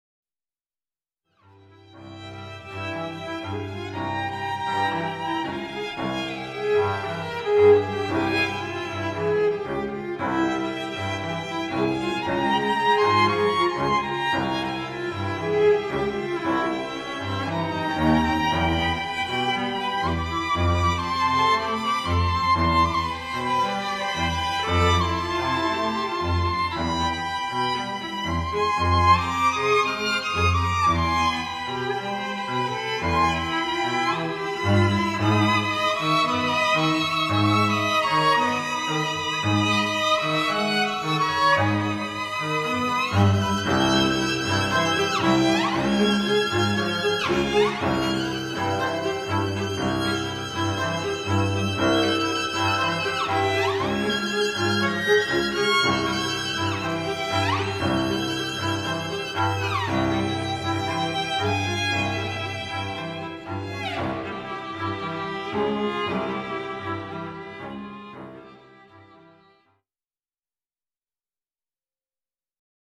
a dreamscape of three Dances for String Quartet.
A mournful Viola and Cello duet conclude the movement.